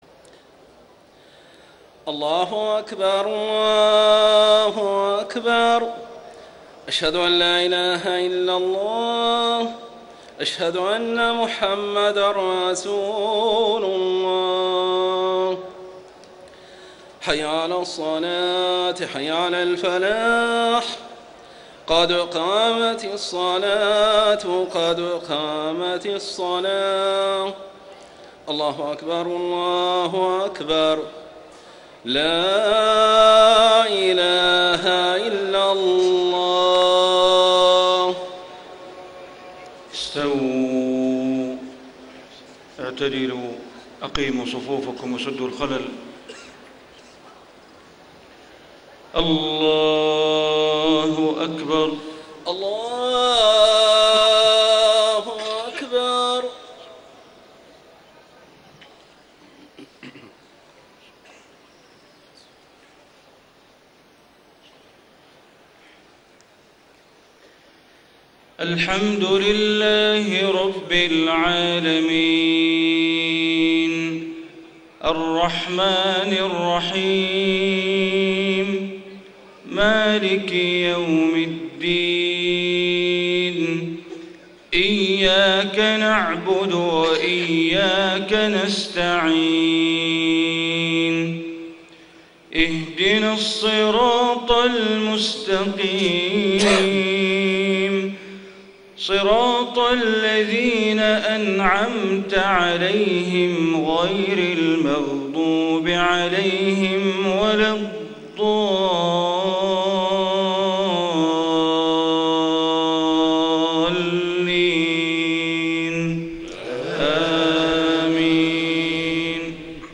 صلاة المغرب 1-4-1435هـ من سورة النور > 1435 🕋 > الفروض - تلاوات الحرمين